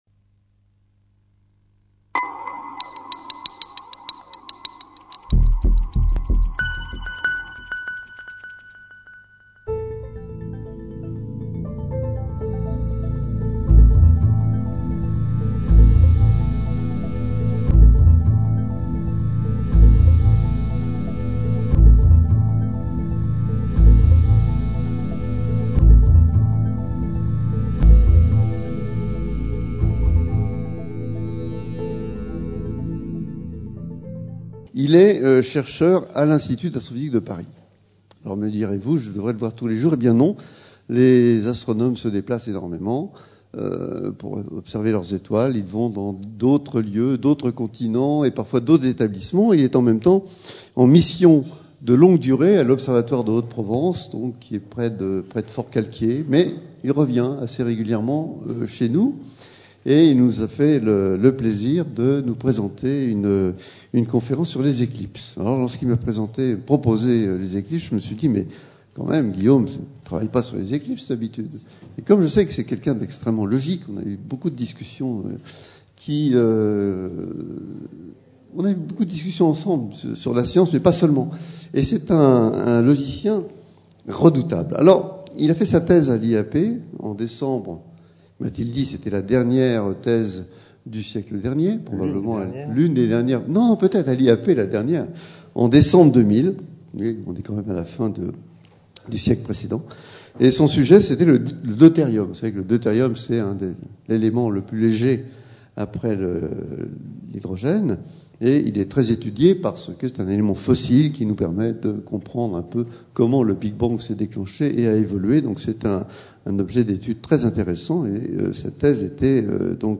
Conférence de l'Institut d'Astrophysique de Paris